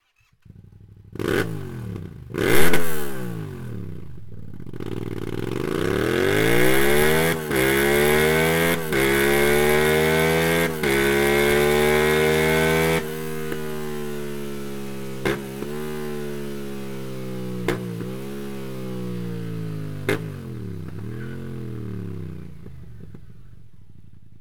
Ein tieferer und sportlicherer Sound steigert den Gesamtvergnügen.
Sound Akrapovic Racing Line Komplettanlage